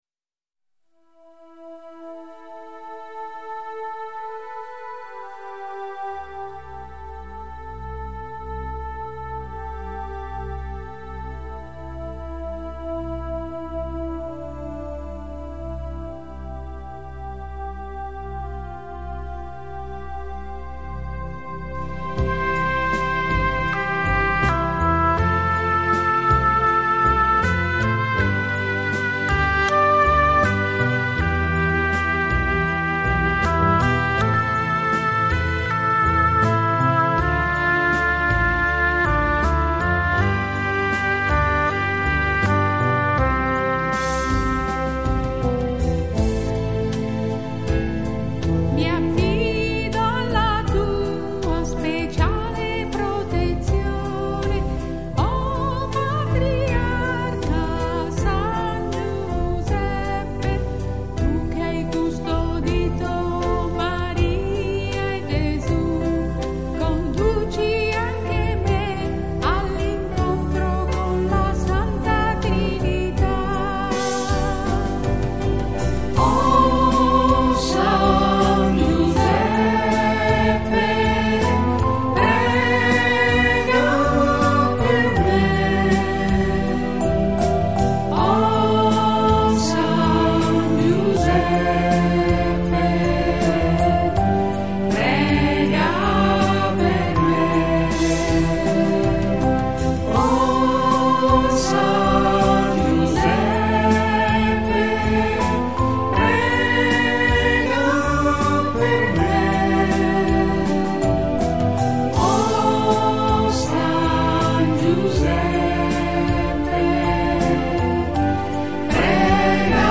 Canto di  "AFFIDAMENTO A S. GIUSEPPE"  su testo con approvazione ecclesastica del Santuario Diocesano a San Giuseppe del Monastero delle Clarisse di Pollenza (MC)
Affidamento a San Giuseppe          T = 80
affidamendo oboe.mp3